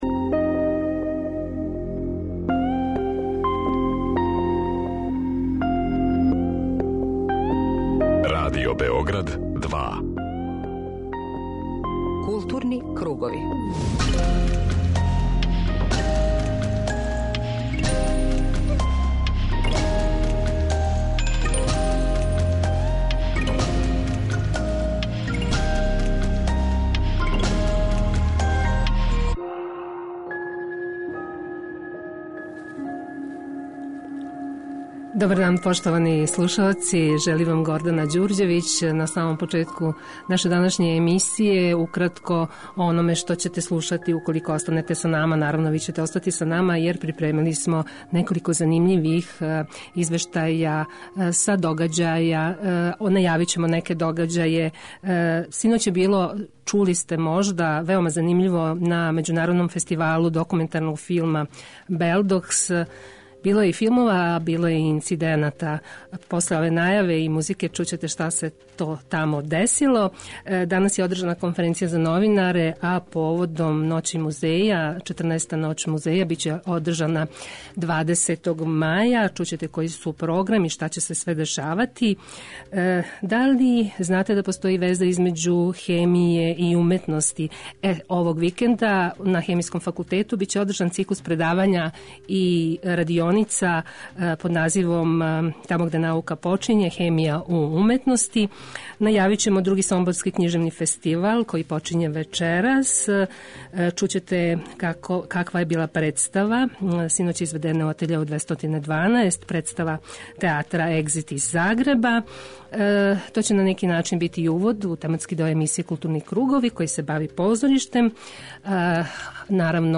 Осим са Кољадом, разговараћемо и са чувеном руском драмском списатељицом Ксенијом Драгунском, која је такође гост фестивала, а по чијем комаду „Осећај браде" у Атељеу 212 наредних дана почињу пробе.
преузми : 41.25 MB Културни кругови Autor: Група аутора Централна културно-уметничка емисија Радио Београда 2.